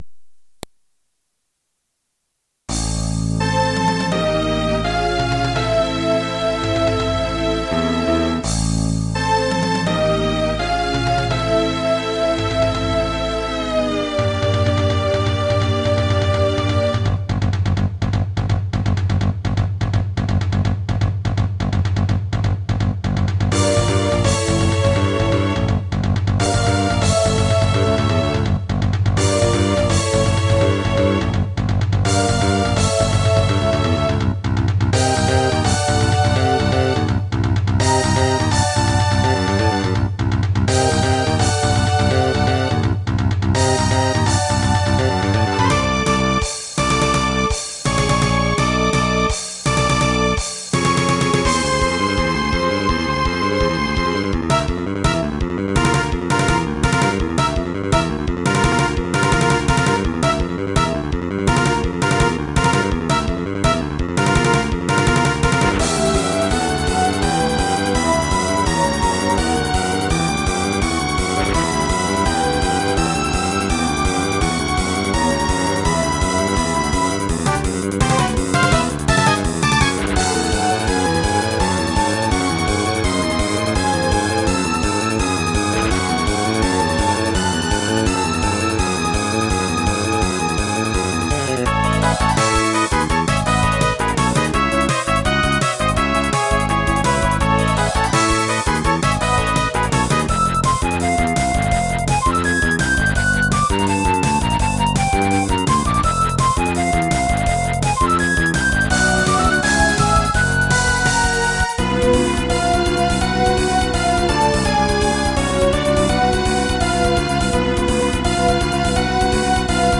管理人が作ったMIDI集です
原曲は超カッコ良いんだが、俺の打ち込みだと、どーも音が薄い。